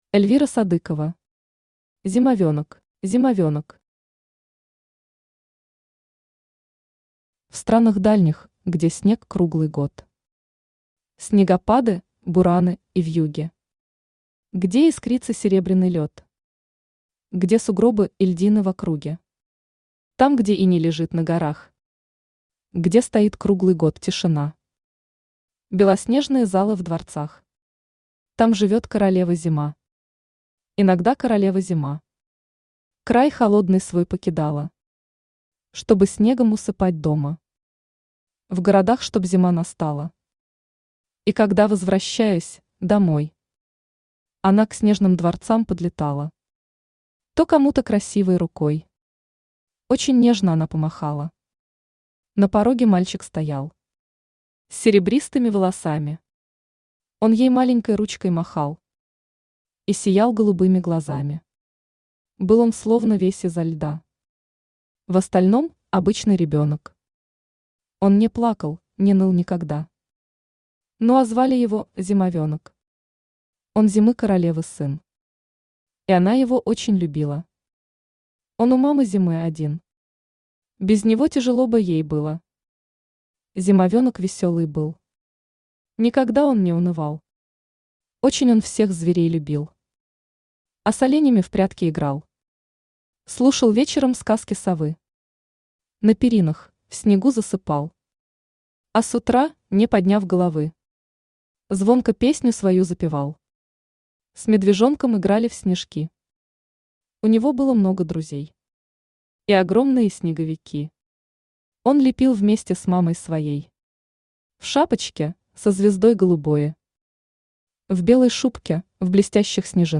Аудиокнига Зимовенок | Библиотека аудиокниг
Aудиокнига Зимовенок Автор Эльвира Альфредовна Садыкова Читает аудиокнигу Авточтец ЛитРес.